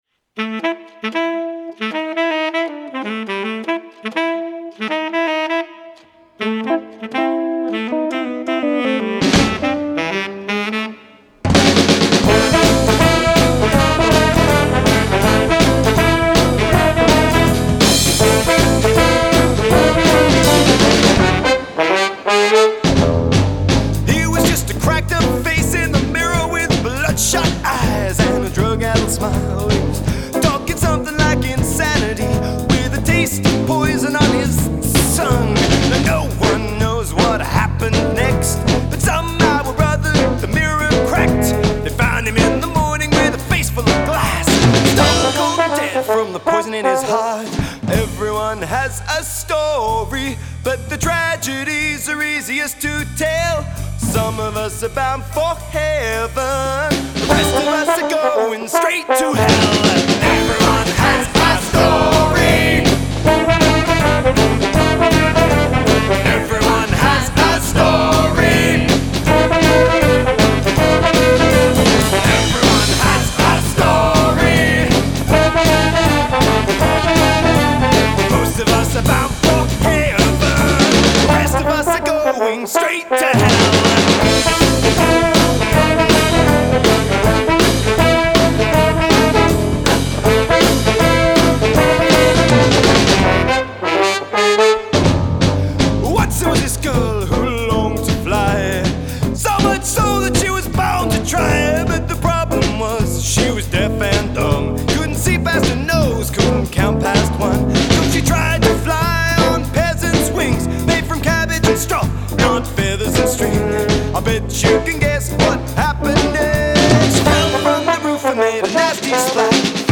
a maddening urge to dance your legs off